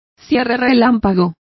Complete with pronunciation of the translation of zips.